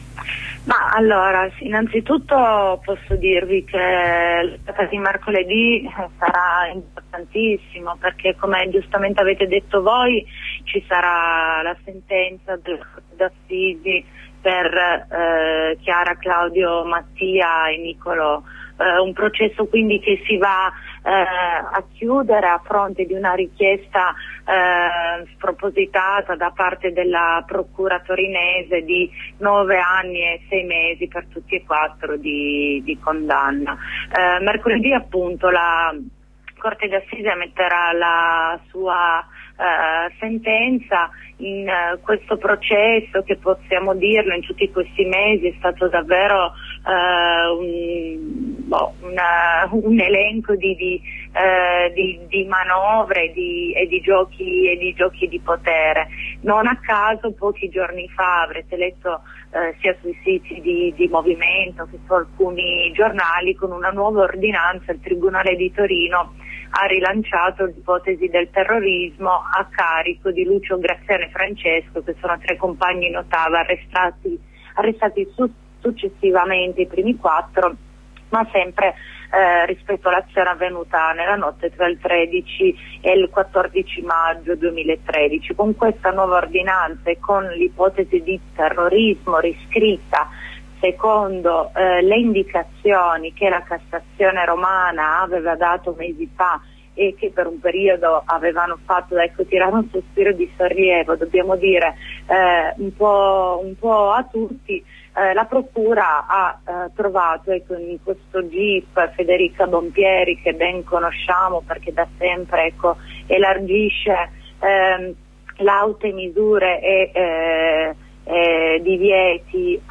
Il movimento no tav ha organizzato dalle ore 9 un presidio davanti al tribunale mentre dalle 17.30 si ritroveranno nella piazza del mercato di Bussoleno. Ai microfoni di Radio Onda Rossa una compagna del movimento no tav.